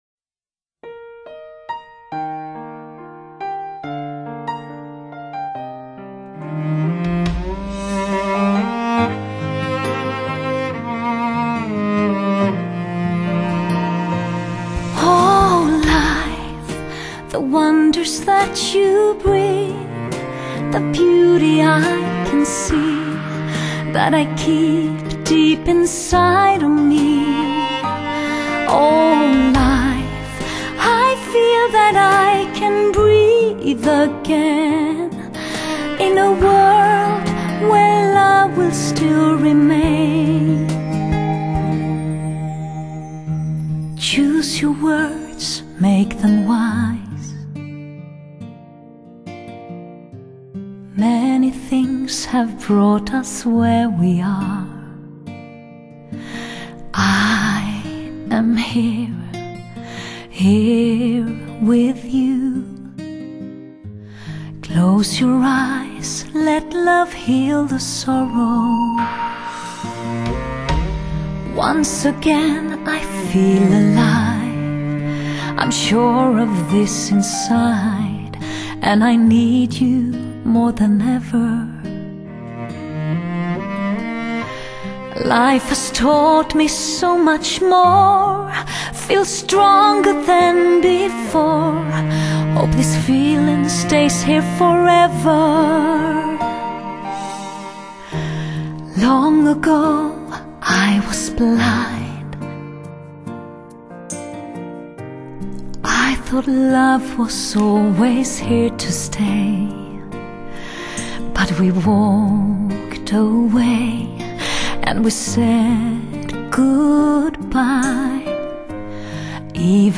版    本:外国人演唱中国流行歌曲